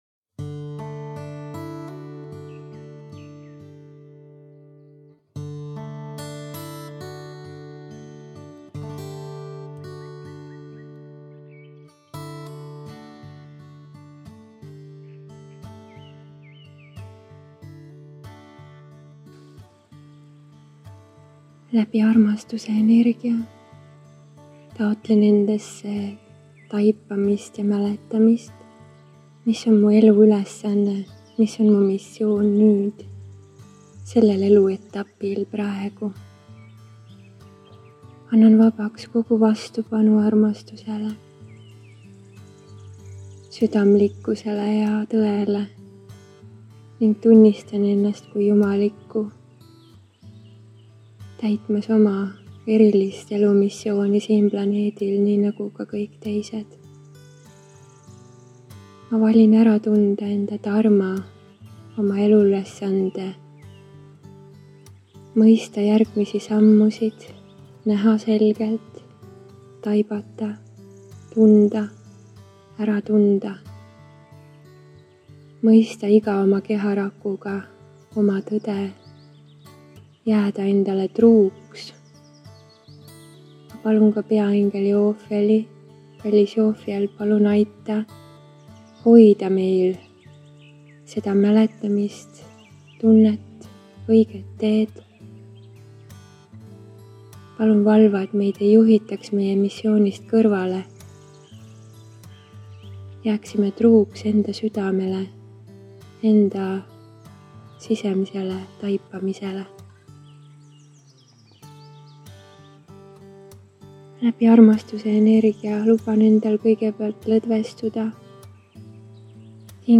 Elu ülesande meditatsioon